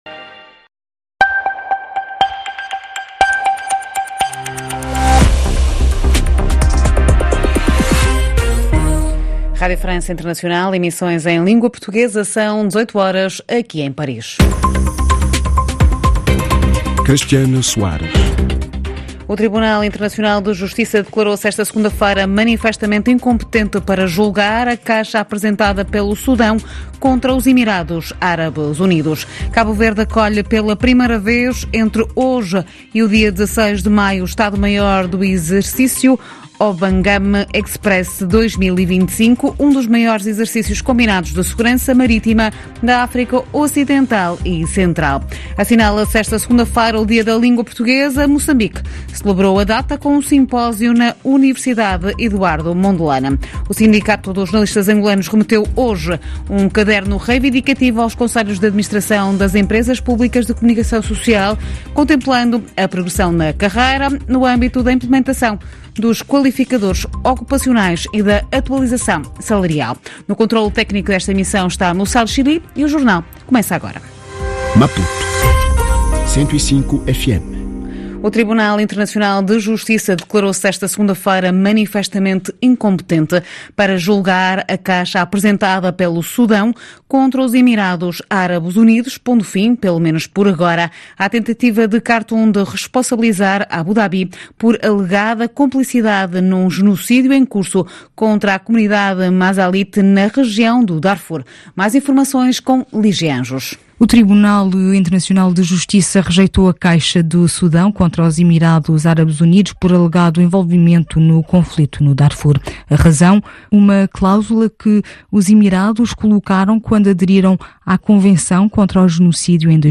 Ouça o jornal